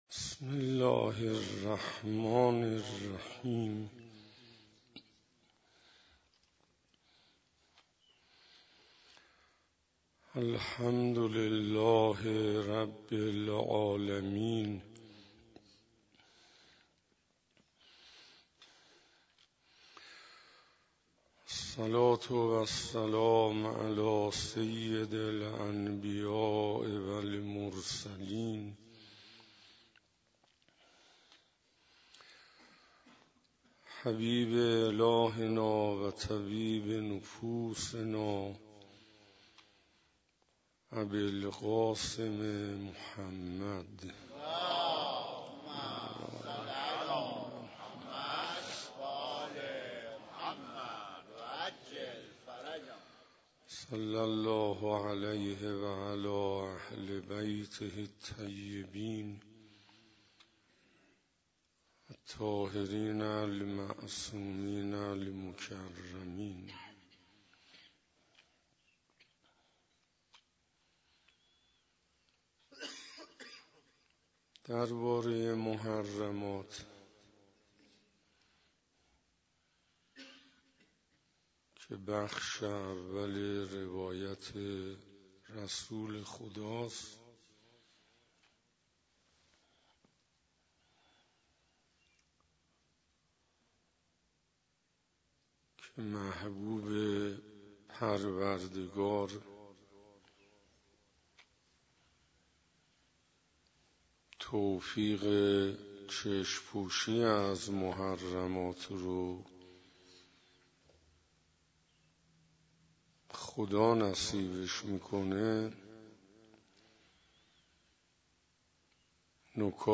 محرم97 - شب نهم - حسینیه هدایت - محبت خداوند